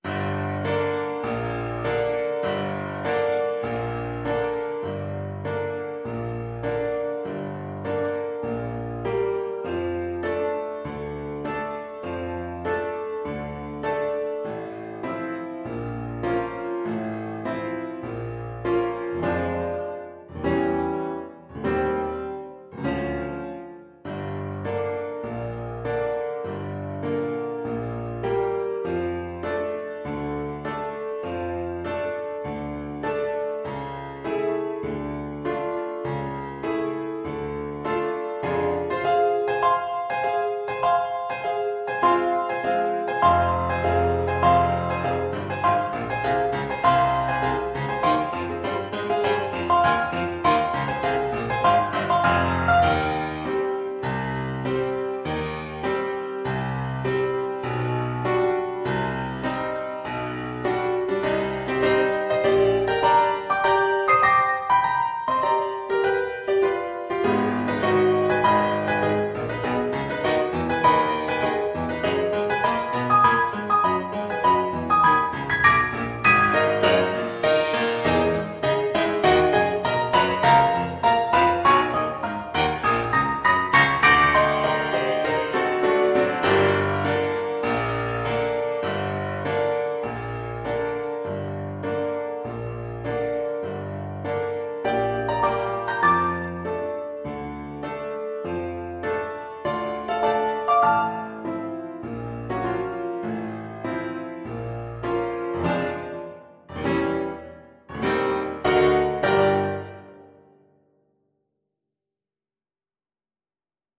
Classical (View more Classical French Horn Music)